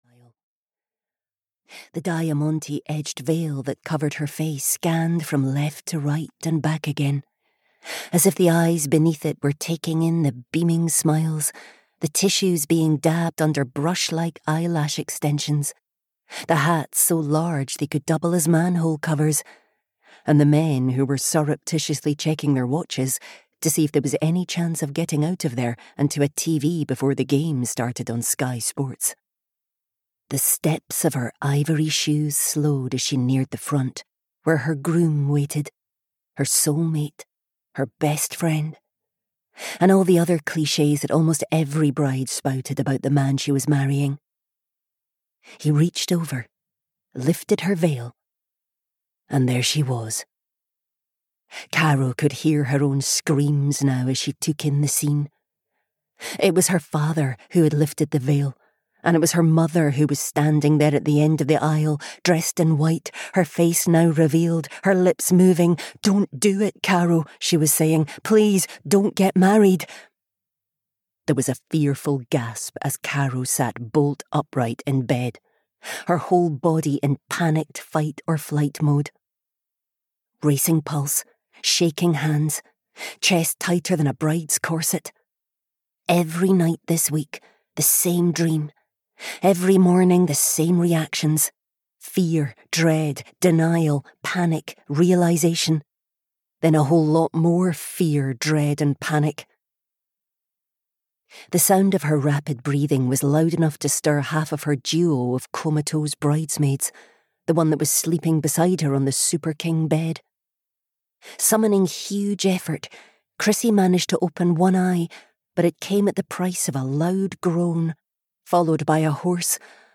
The Last Day of Winter (EN) audiokniha
Ukázka z knihy